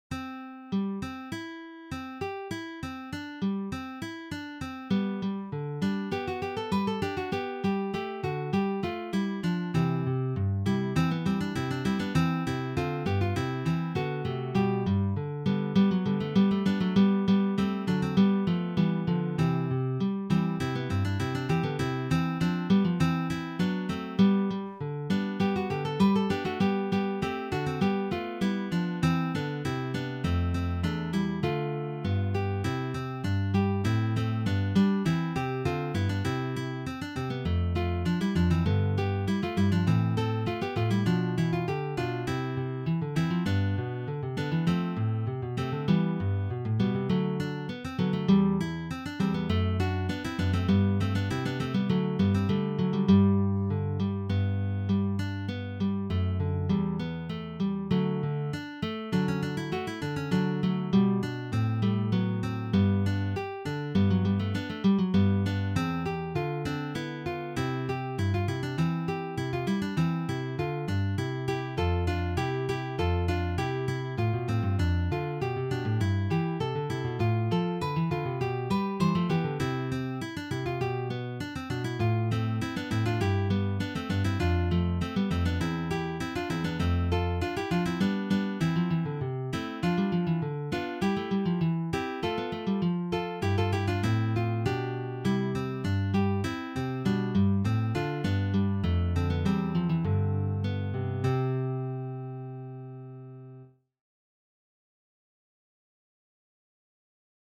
for three guitars
This is from the Baroque period.